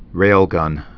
(rālgŭn)